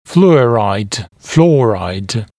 [‘fluəraɪd] [‘flɔːraɪd][‘флуэрайд] [‘фло:райд]фторид